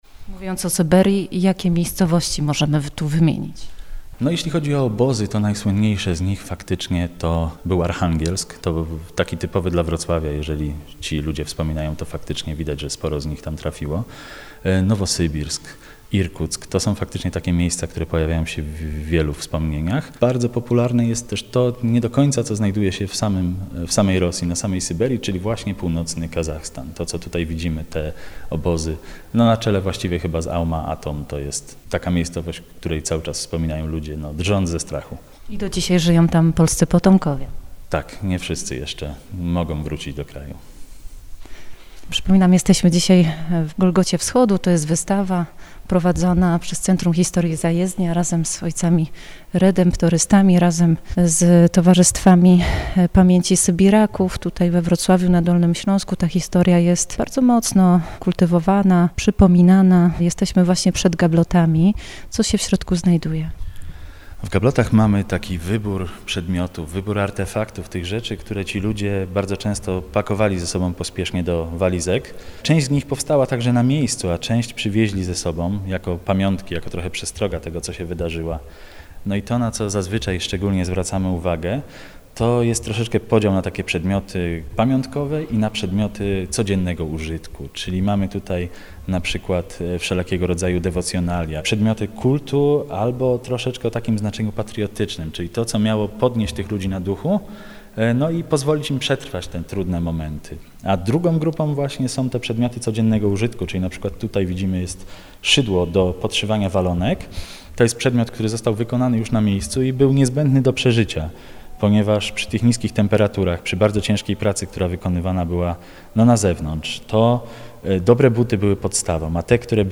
Rozmowa o przesiedleniach i powrotach w piątek 14 czerwca po godz. 14:00.